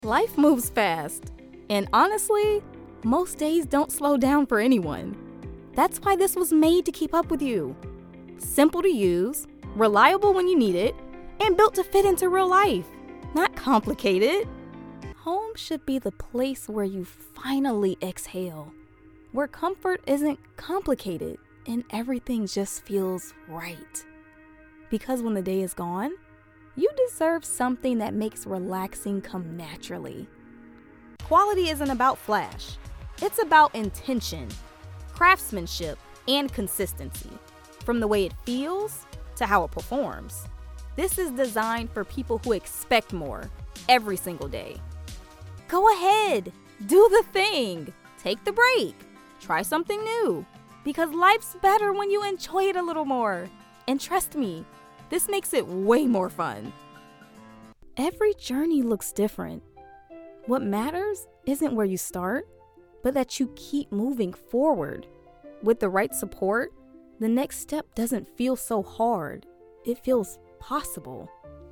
Commercial Demo
Conversational • Trustworthy • Lifestyle
Clean, broadcast-ready audio from my home setup:
• Microphone: RØDE NT1 (5th Gen) — XLR + USB
commercial-demo.mp3